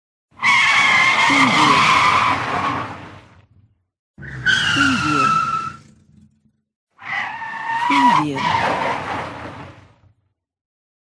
دانلود آهنگ ترمز ماشین 2 از افکت صوتی حمل و نقل
دانلود صدای ترمز ماشین 2 از ساعد نیوز با لینک مستقیم و کیفیت بالا
جلوه های صوتی